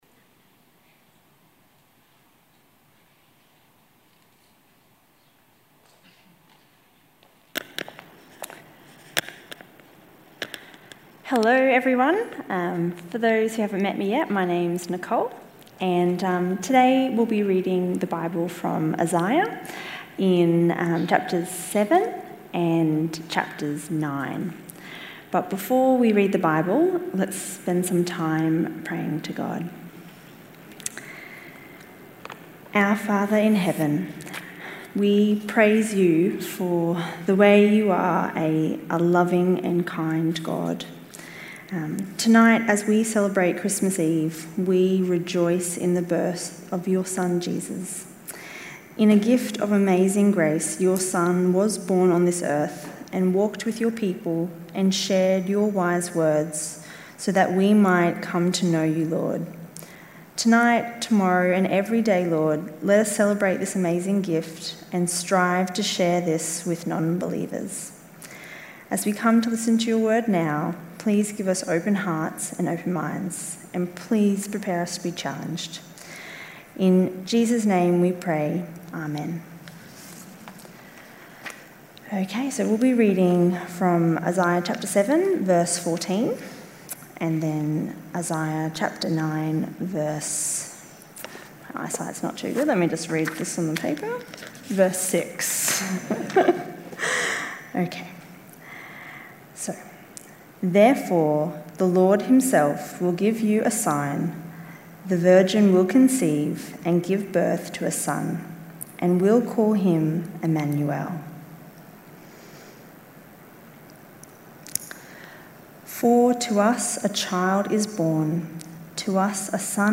Talk.mp3